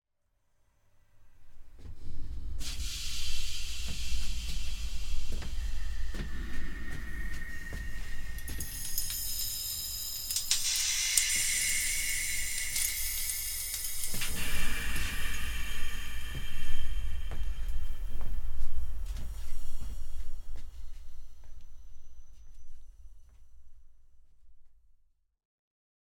interactive installation for computer, four loudspeakers, one microphone,
metal tubes, cloth sheets, jute bags and other scrap
Passing through a curtain,